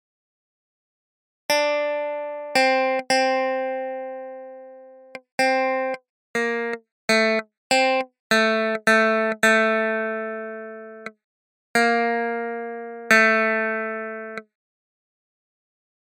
Key written in: F Major
Each recording below is single part only.
a reed organ